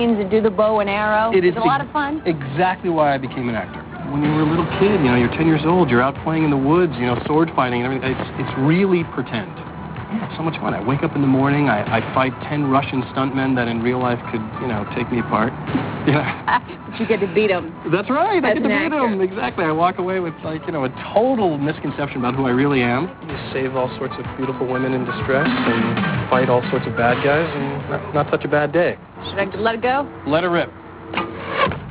Here is a couple of clips from an interview he did with Access Hollywood.